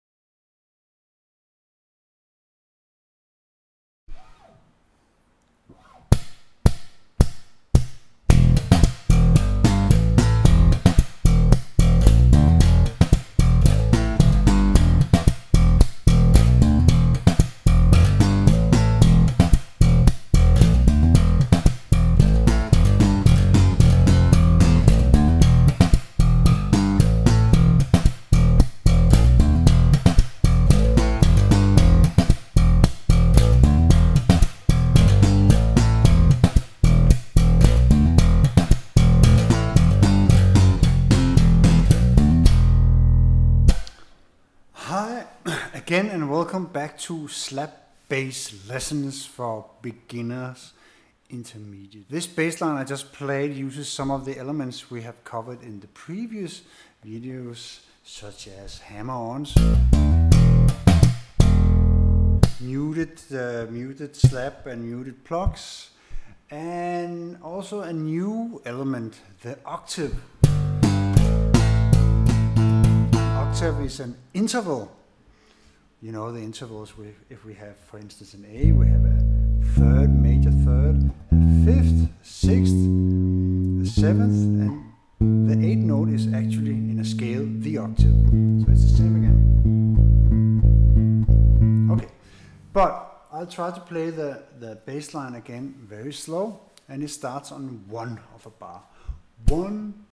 06 Slap bass 101 for novice slappers
06-Slap-bass-101Sample.mp3